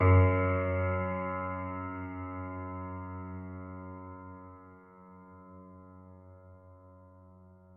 piano-sounds-dev
Steinway_Grand